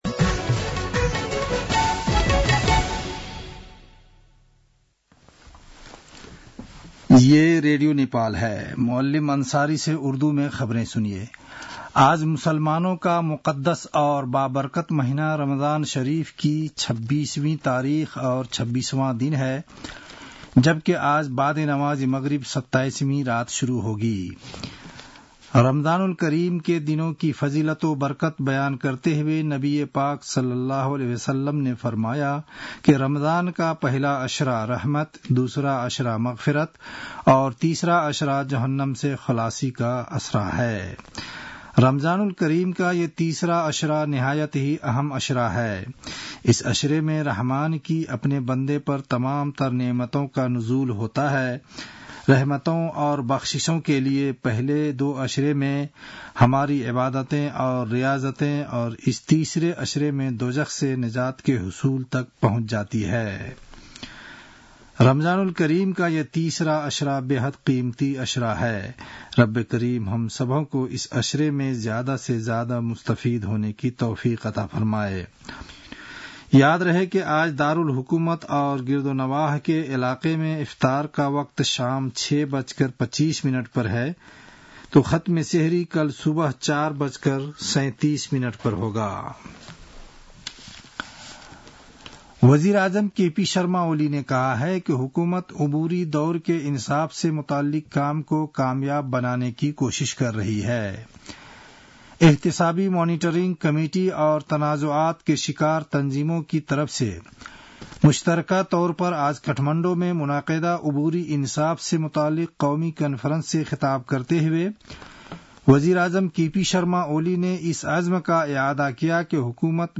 उर्दु भाषामा समाचार : १४ चैत , २०८१
Urdu-news-12-14.mp3